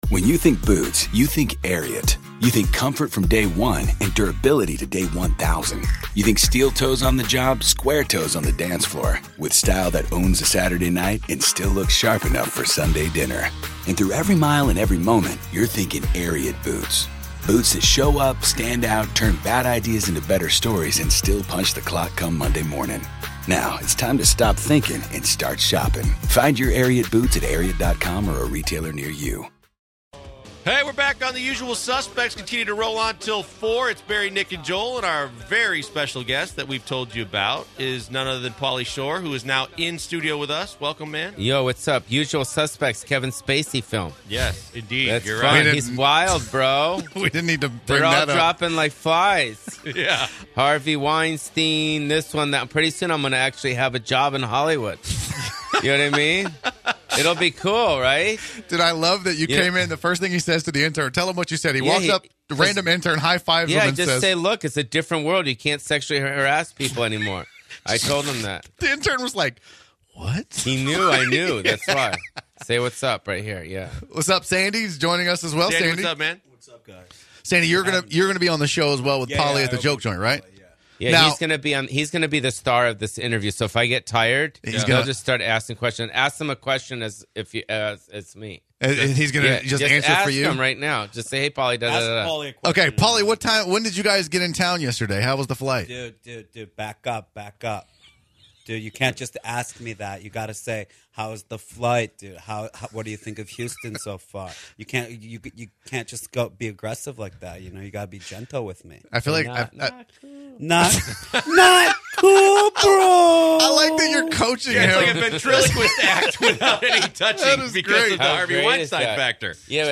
11/8/2017 Paulie Shore joins The Usual Suspects in studio